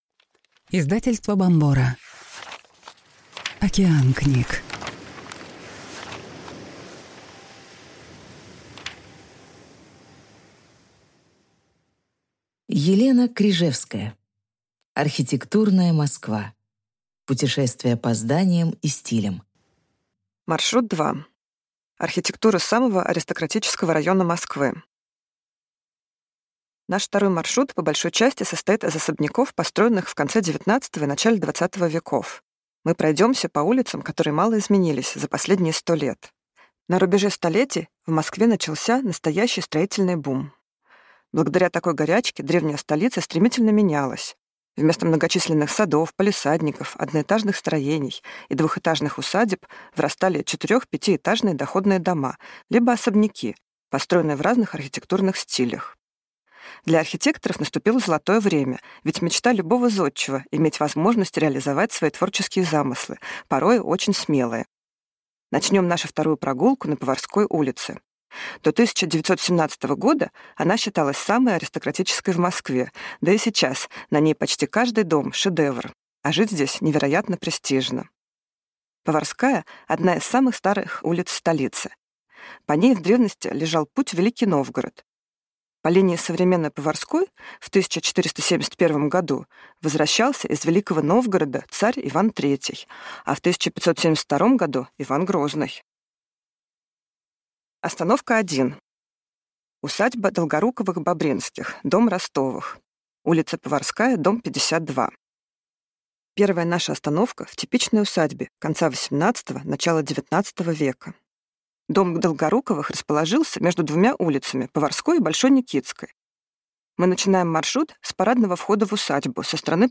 Аудиокнига Архитектура самого аристократического района Москвы | Библиотека аудиокниг